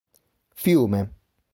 It-Fiume.ogg.mp3